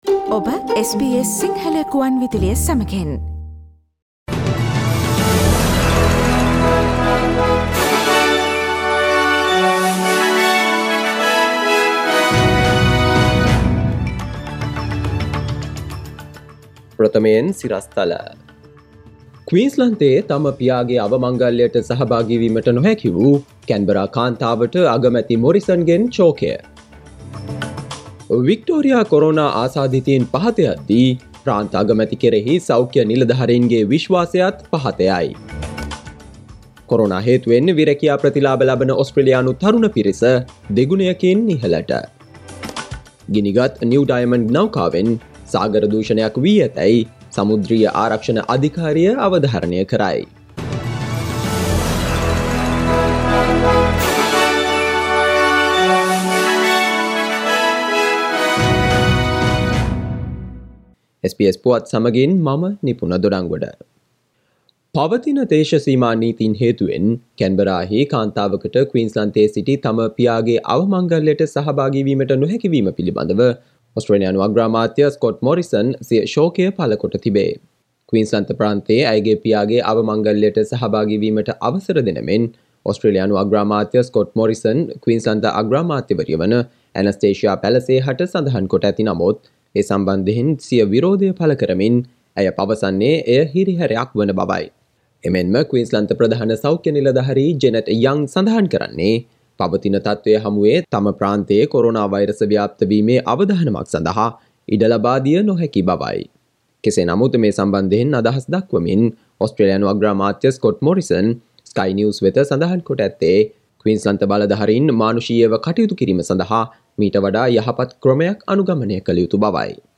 Today’s news bulletin of SBS Sinhala radio – Friday 11 September 2020.